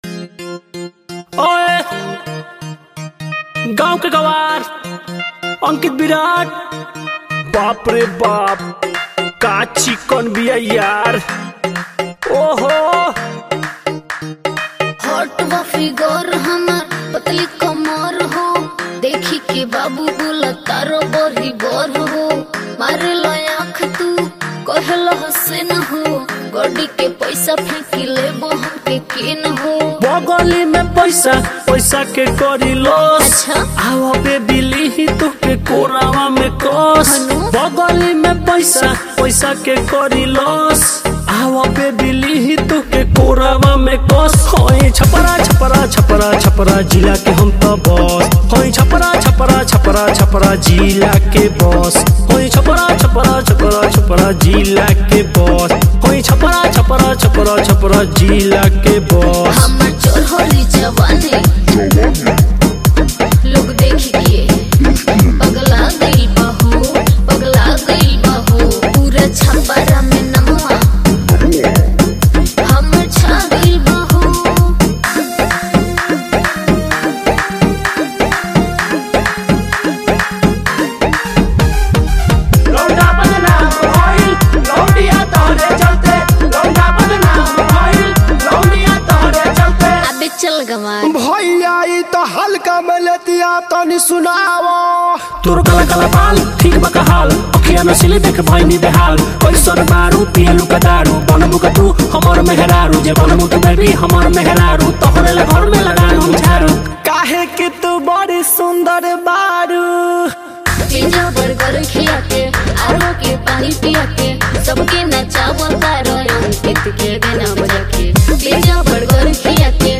Bhojpuri rap song